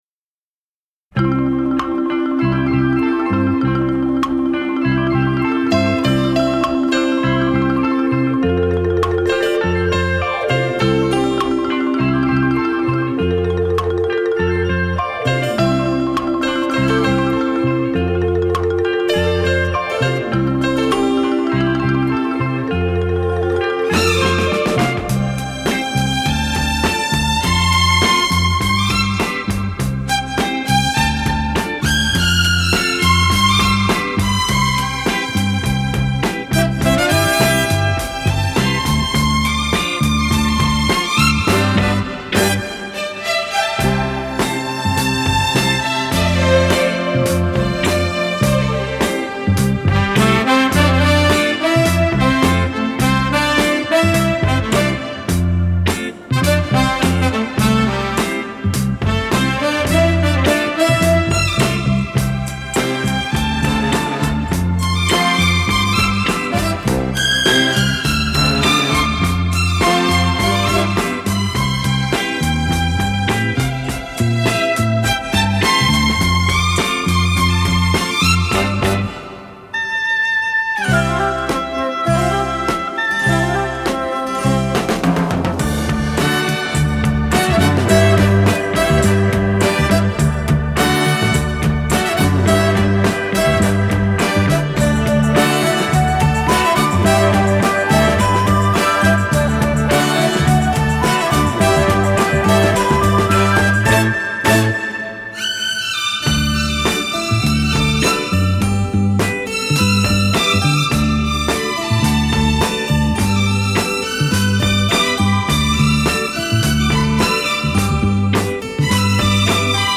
Media Vinyl Records
Genre Easy Listening